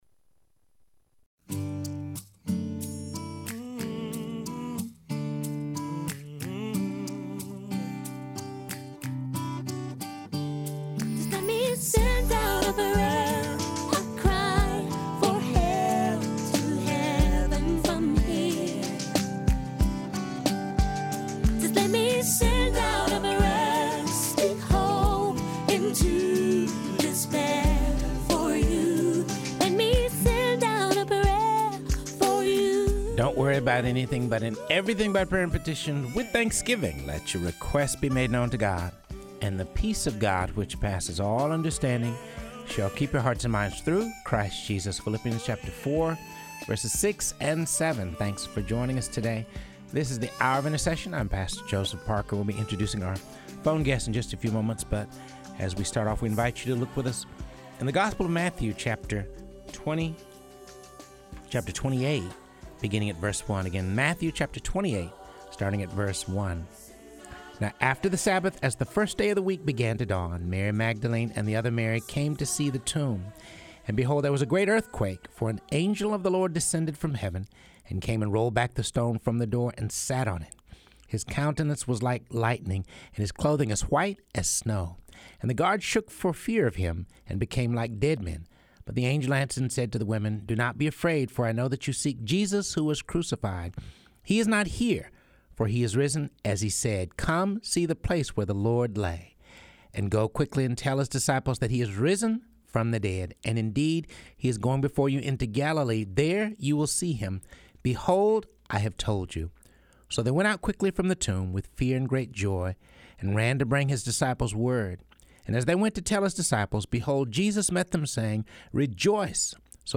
is joined via phone by Columbus, Mississippi Kingdom Vision church